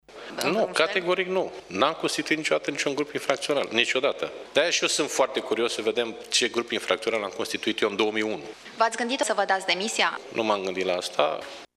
Președintele Camarei Deputaților a declarat că nu s-a gândit să își dea demisia, precizând că miza acestui dosar este preluarea PSD: